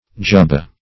Search Result for " jubbah" : The Collaborative International Dictionary of English v.0.48: Jubbah \Jub"bah\, n. Also Jubbeh \Jub"beh\, Joobbeh \Joob"beh\ [Hind. jubba, fr. Ar. jubbah.]